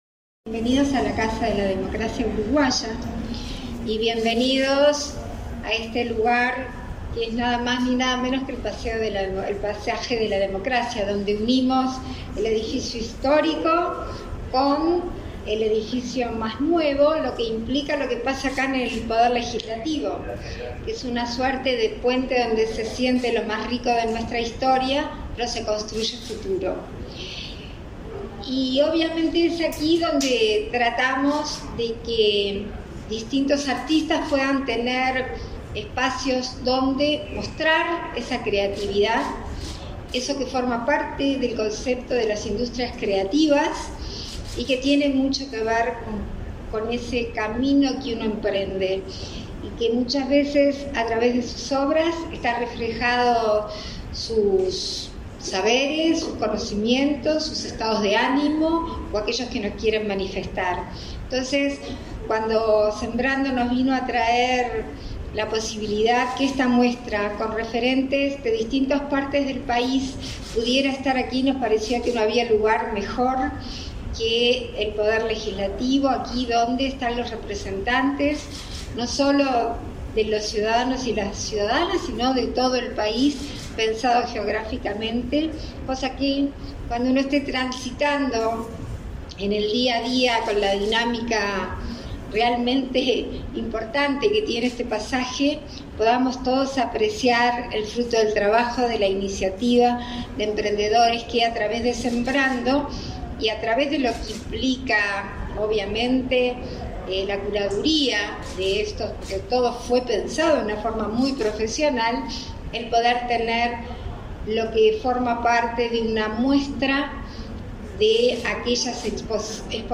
Palabras de la vicepresidenta de la República en ejercicio de la presidencia, Beatriz Argimón
Con la presencia de la vicepresidenta de la República en ejercicio de la presidencia, Beatriz Argimón, fue inaugurada, este 1.° de noviembre, la